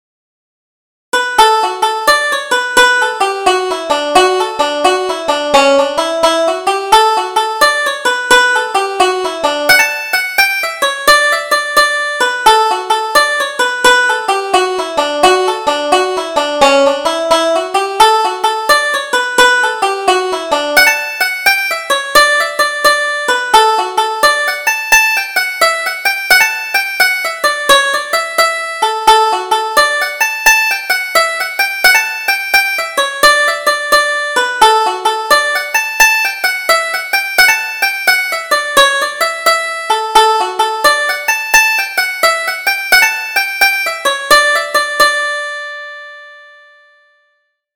Double Jig: The Girls of Banbridge